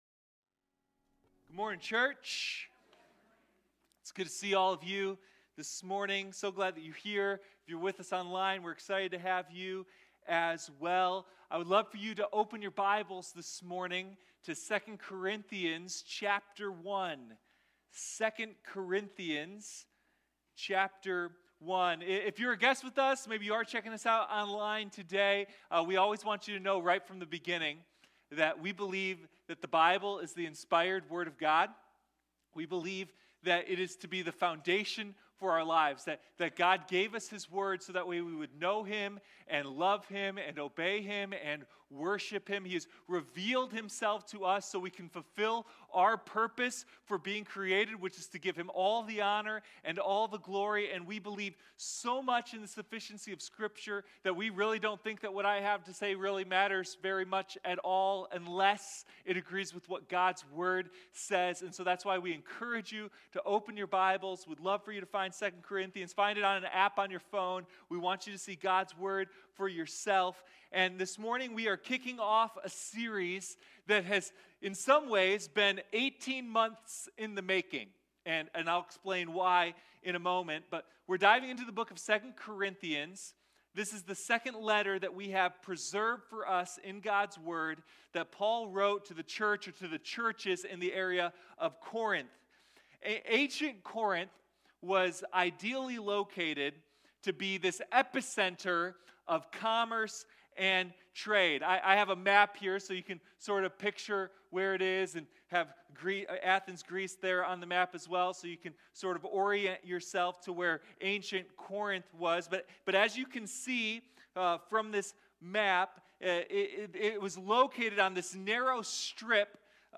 Sunday Morning God's Power in our weakness: 2 Corinthians